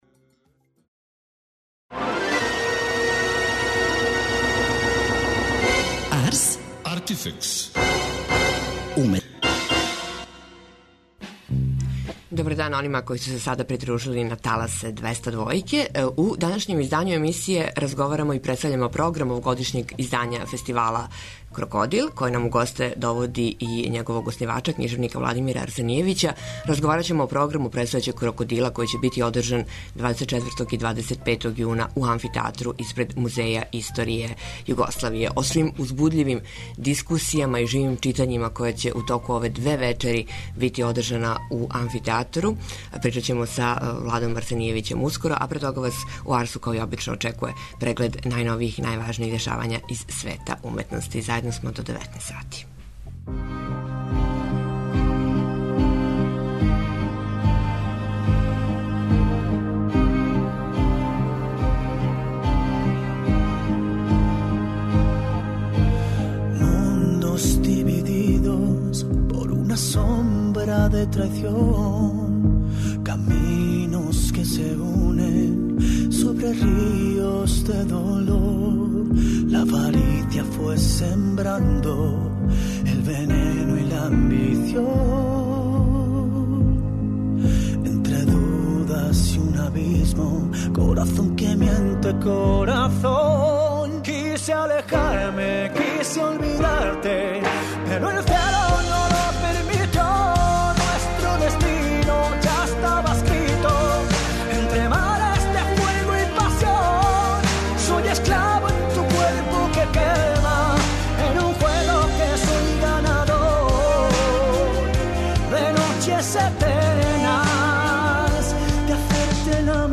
Фестивал Крокодил (Књижевно Регионално Окупљање Које Отклања Досаду И Летаргију) у госте нам доводи његовог оснивача књижевника Владимира Арсенијевића.